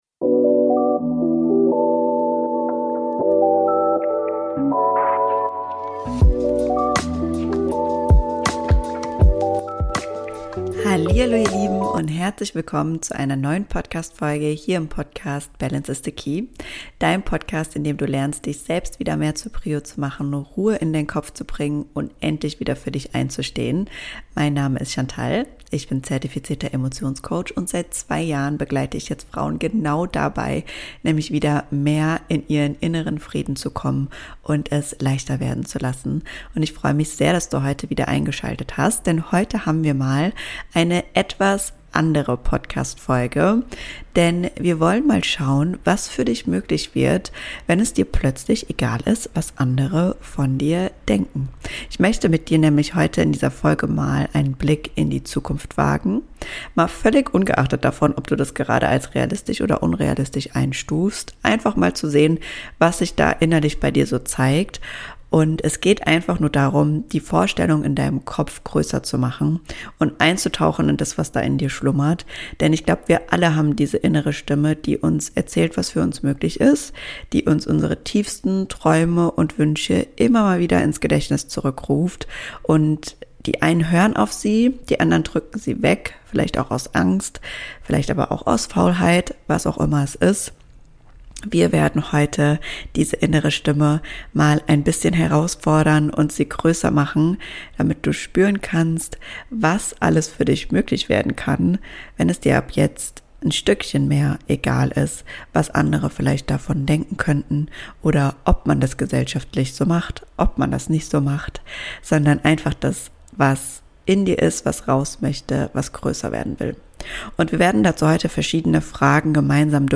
In dieser Podcast Folge erwartet dich eine Mischung aus Meditation und Input.